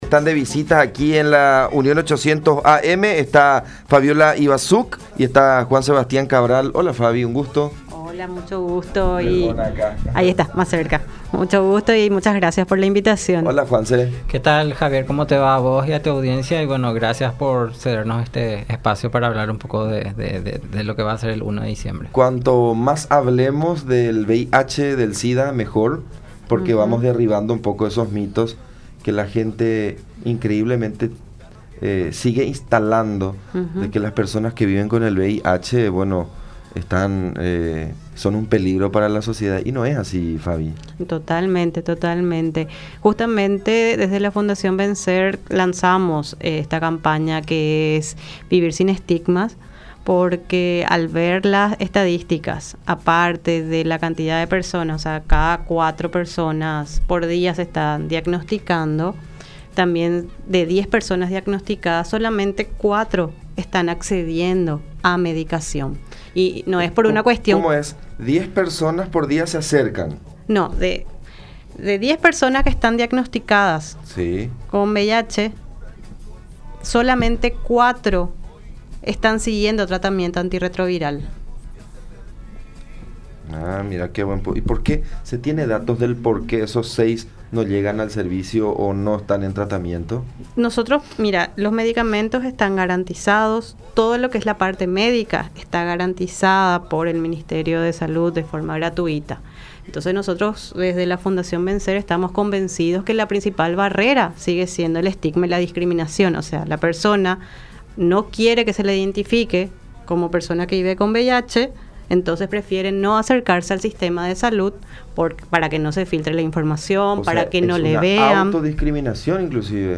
entrevista con La Unión R800 AM